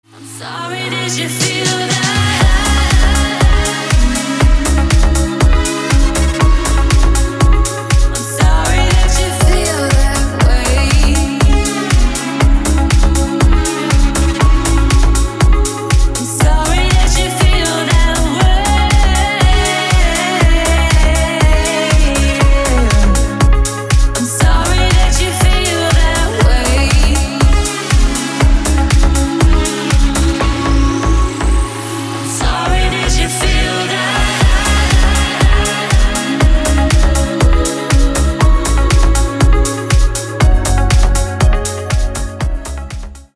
• Качество: 320, Stereo
громкие
женский вокал
deep house
dance
EDM
электронная музыка
чувственные